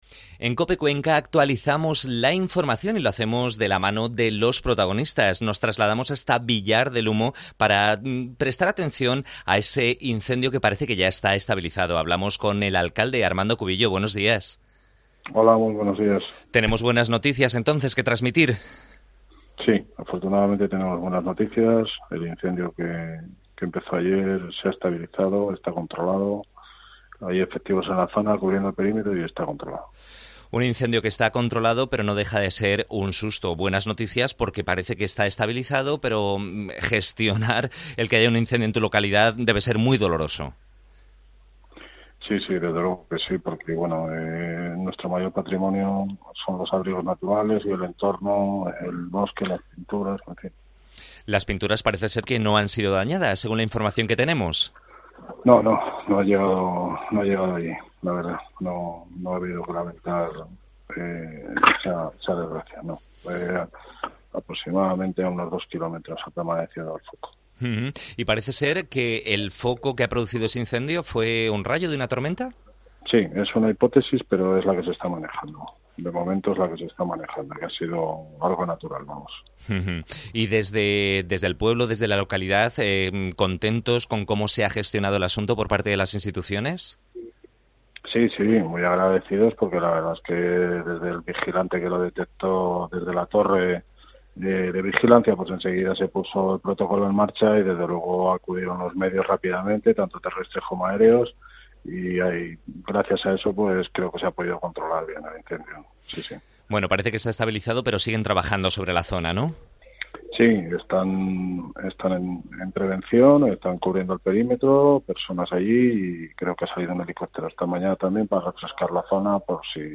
AUDIO: Entrevista a Armando Cubillo, alcalde de la localidad